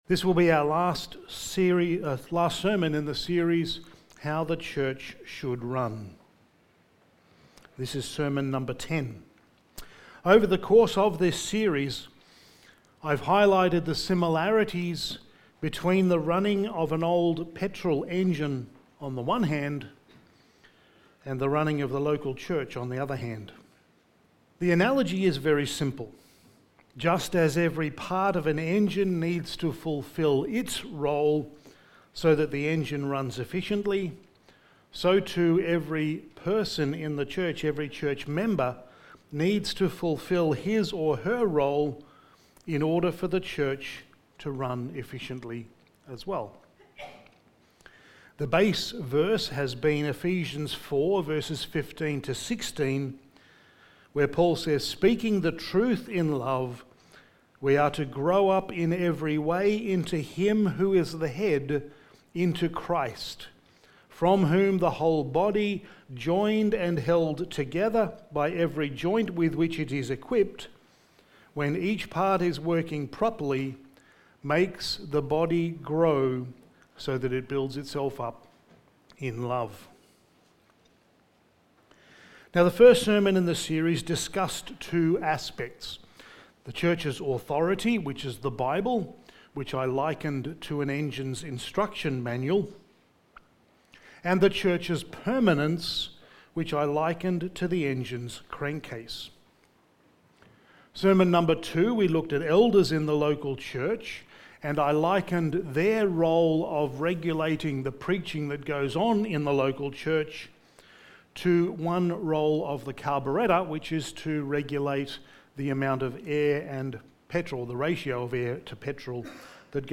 Sermon
Passage: Matthew 18:15-20 Service Type: Special Event